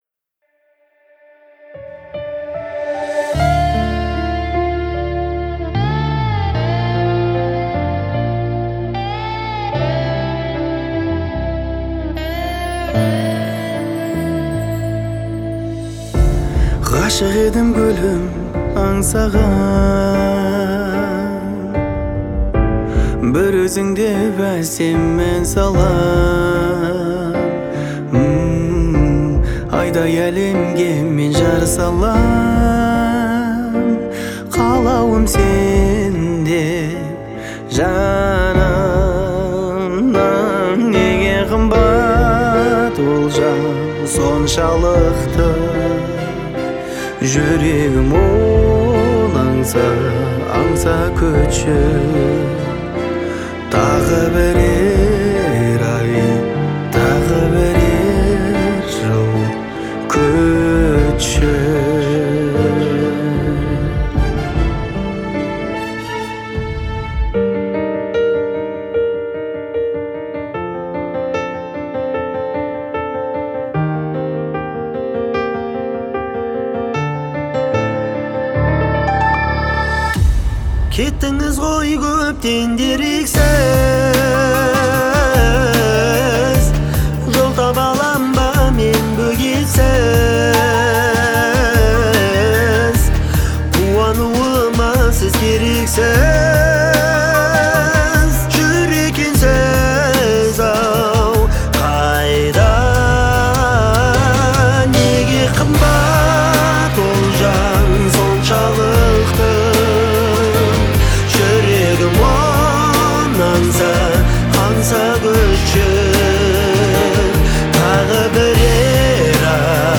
это трек в жанре поп с элементами казахской музыки